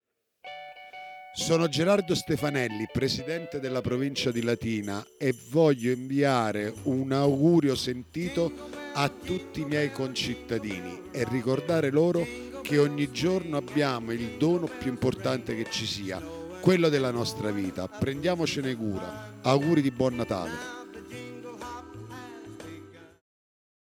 LATINA – Un augurio speciale, quello del presidente della Provincia di Latina Gerardo Stefanelli, con tanto di musiche natalizie di sottofondo.